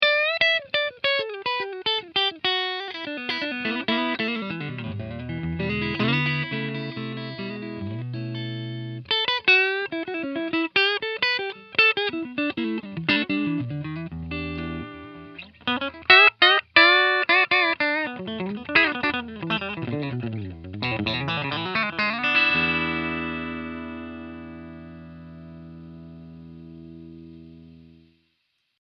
Country riff 2